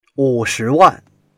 wu3shi2wan4.mp3